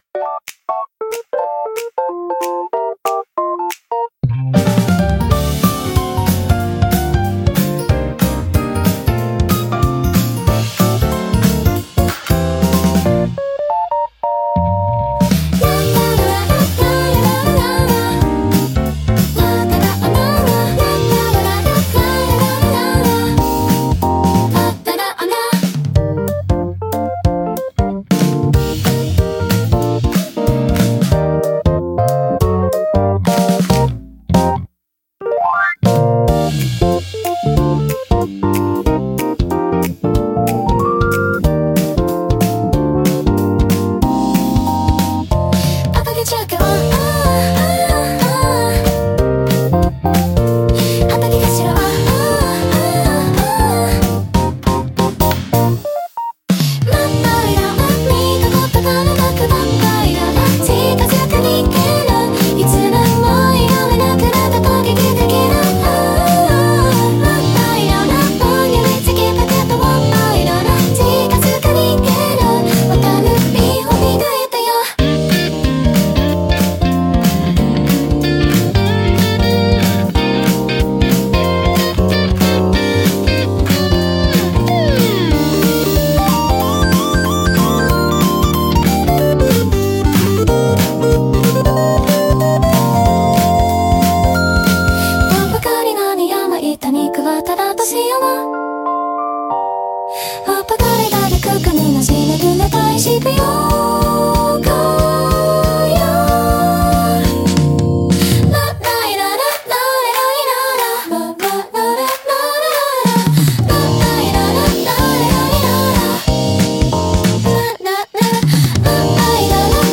元気で前向きな印象を与え、若年層やファミリー向けコンテンツに特によく合います。